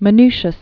(mə-nshəs, -shē-əs, -ny-), Aldus 1450-1515.